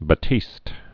(bə-tēst, bă-)